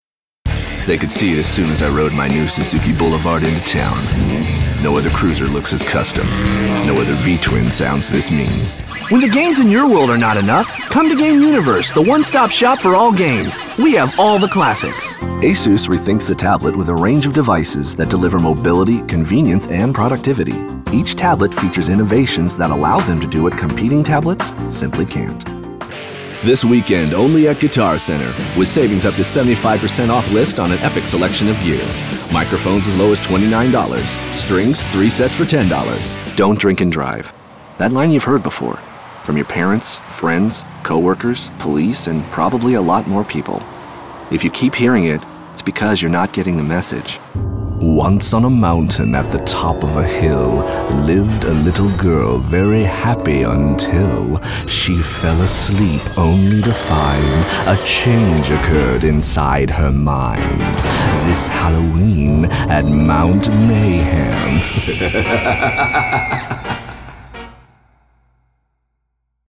Voice Over Demos